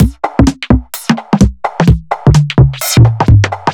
Index of /musicradar/uk-garage-samples/128bpm Lines n Loops/Beats
GA_BeatResC128-04.wav